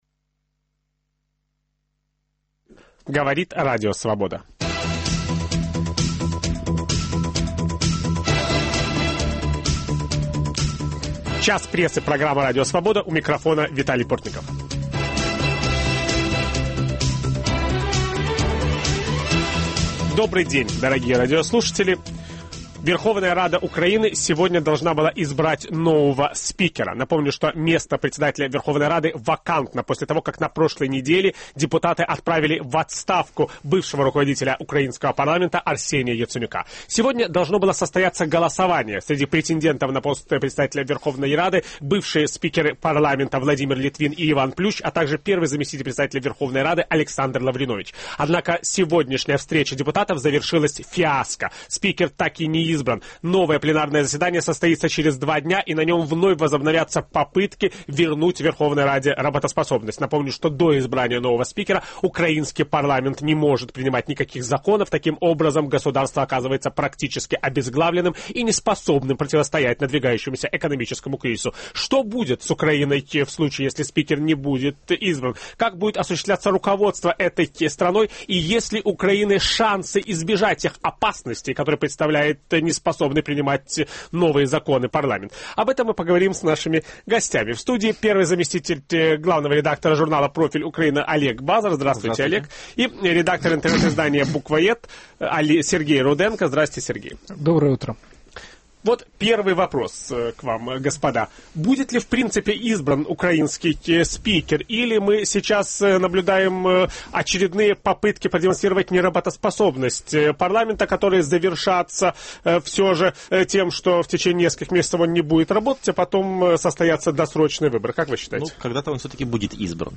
Украинский парламент в поисках нового спикера. Ведущий программы Виталий Портников беседует